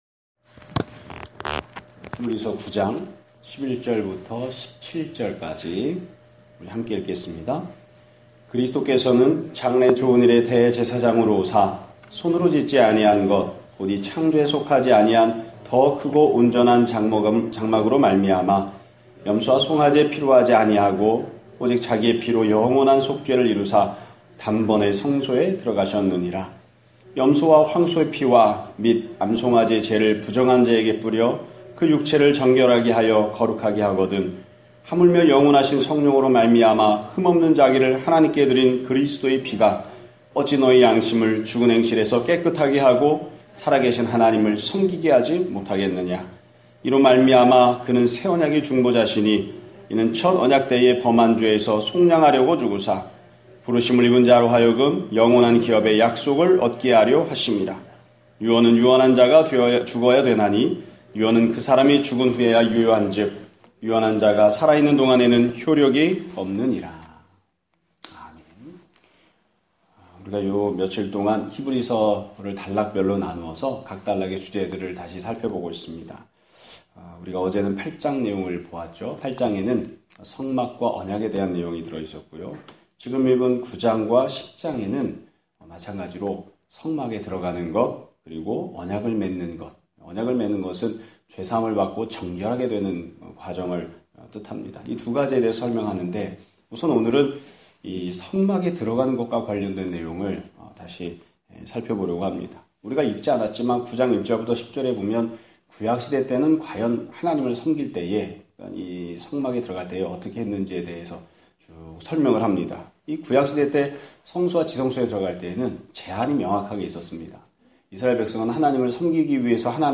2017년 11월 28일(화요일) <아침예배> 설교입니다.